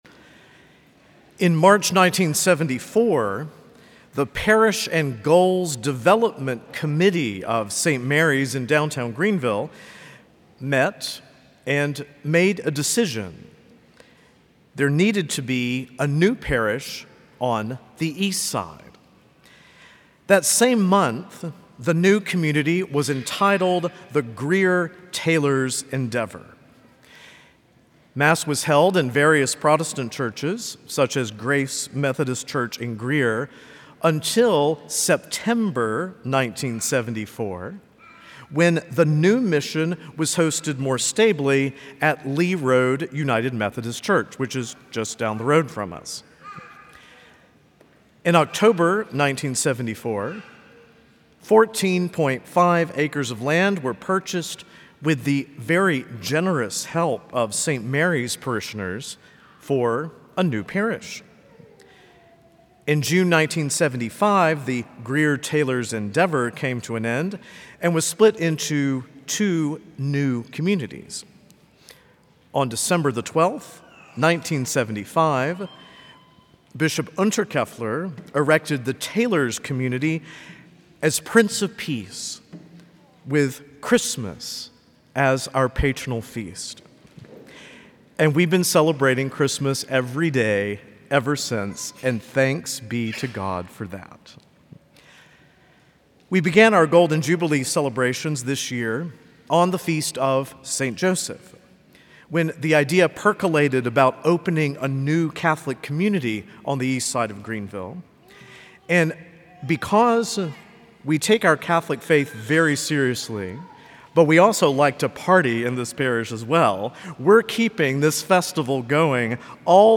From Series: "Homilies"
Homilies that are not part of any particular series.